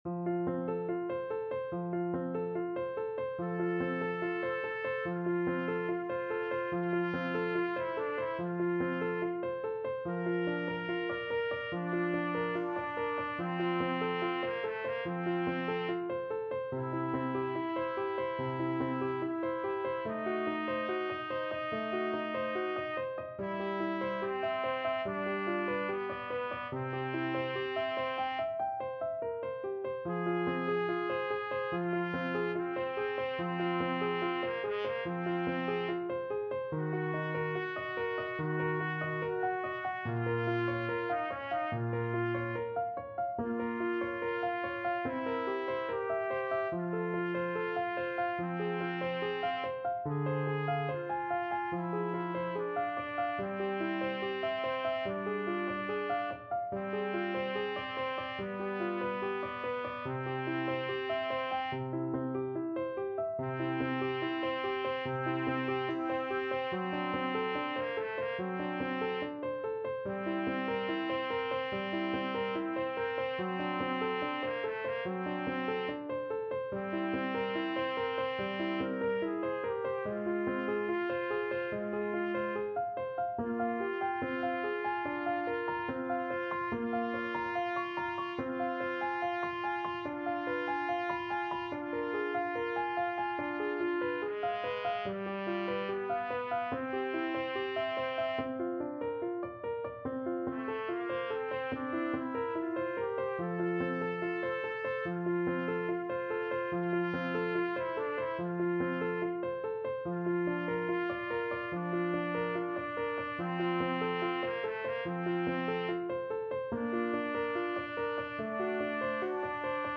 Andante =72
Classical (View more Classical Trumpet Duet Music)